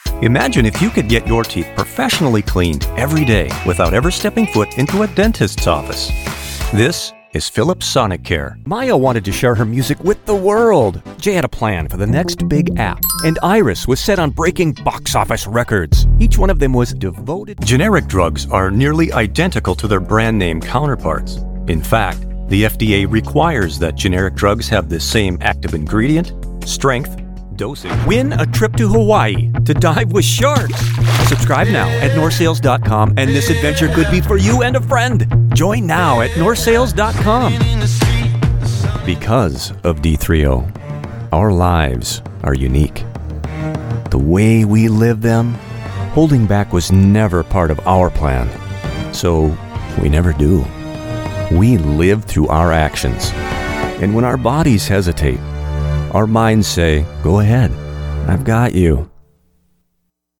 Professionally trained. Pro gear and studio.
Conversational - Personable - Guy Next Door style of voice. Believable and Friendly.
middle west
Sprechprobe: Werbung (Muttersprache):